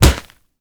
punch_grit_wet_impact_02.wav